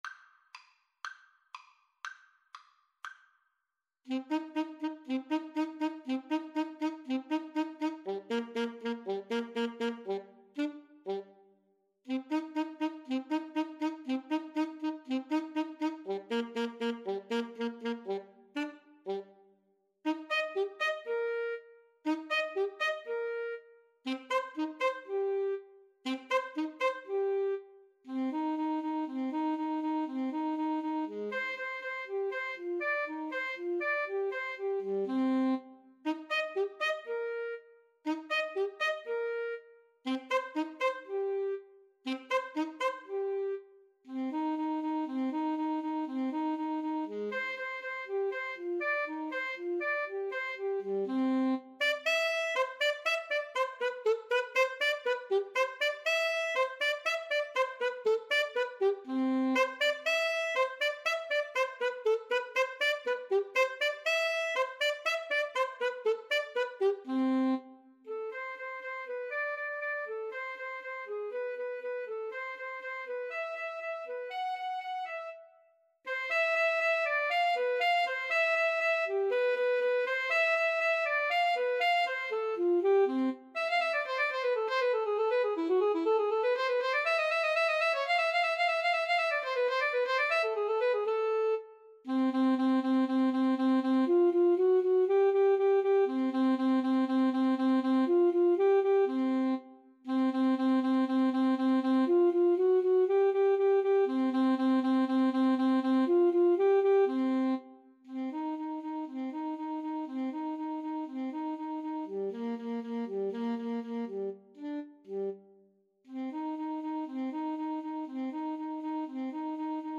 Free Sheet music for Flute-Saxophone Duet
C minor (Sounding Pitch) (View more C minor Music for Flute-Saxophone Duet )
Allegro con brio (View more music marked Allegro)
Classical (View more Classical Flute-Saxophone Duet Music)